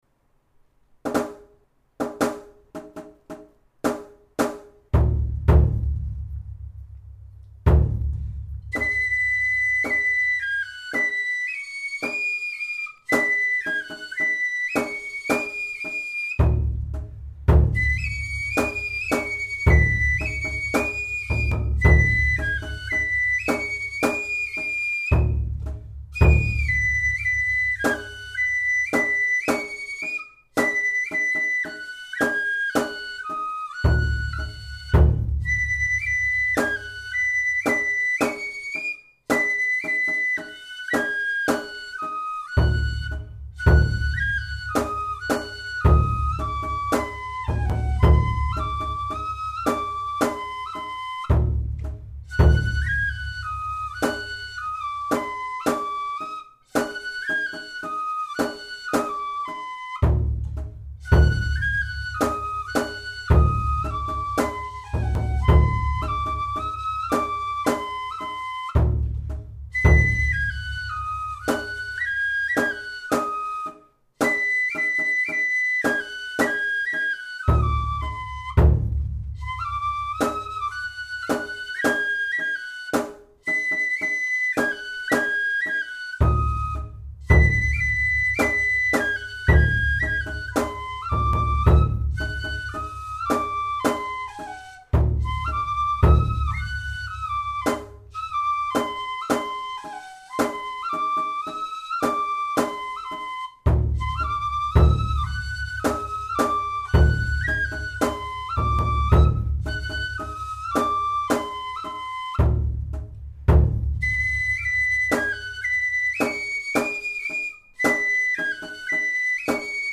このページの音源は、あくまで練習用に録音したもので、装飾音符をすべて省いて、きわめて簡略化された形で吹いています。
太鼓は、コンピューターで作成したもので、リズムは完璧ですが、やや無味乾燥です。
笠寺では、笛が先に始まって後から太鼓が始まる方が一般的ですが、音源に合せて吹きやすいように、太鼓から入るパターンで吹いています。
速度は１１０と、やや遅いテンポで吹いています。また、太鼓の前奏の後、本来、２小節太鼓は休みですが、リズムがとりやすいように、小さな締太鼓を入れてあります。
笛・太鼓